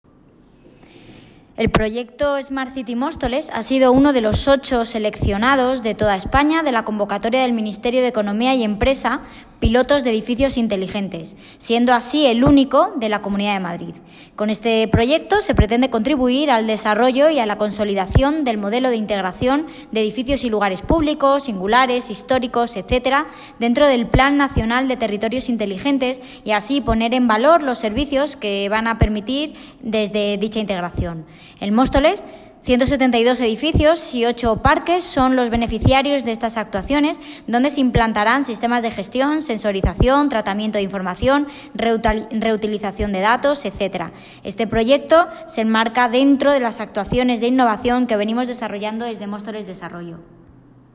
Audio - Jessica Antolín, (Concejala de Desarrollo, Económico, Empleo y Nuevas Tecnologías) sobre proyecto Smart City